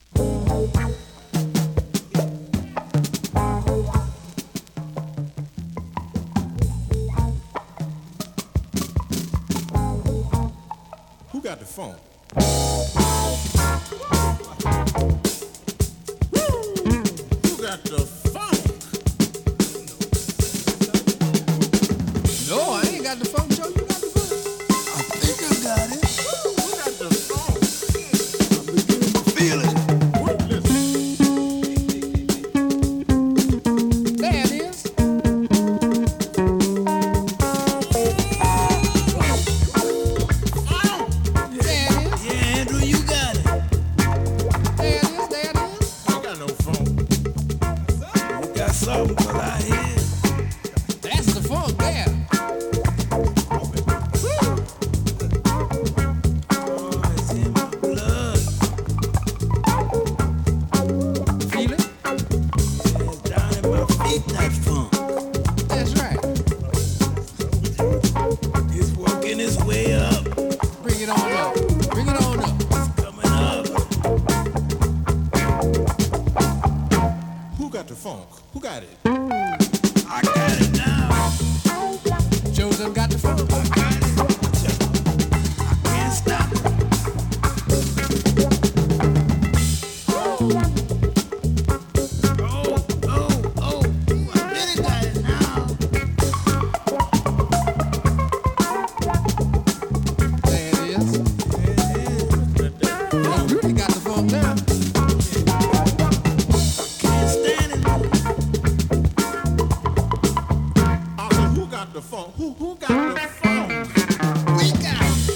類別 R&B、靈魂樂
Great deep funk !!
試聴 (実際の出品物からの録音です)